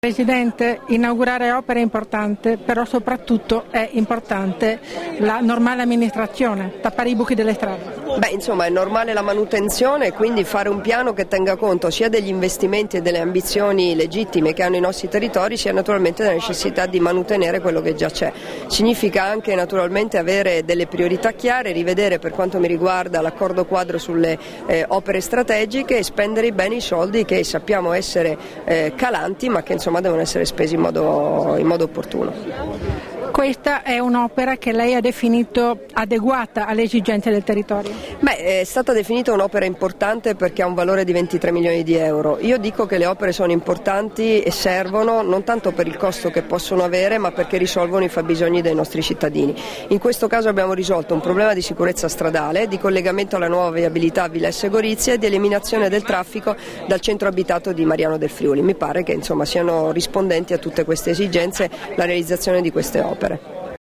Ascolta le dichiarazioni di Debora Serracchiani rilasciate a margine dell'inaugurazione del nuovo by-pass sulla strada regionale 305, a Mariano del Friuli l'11 giugno 2013 - Formato MP3 [1026KB]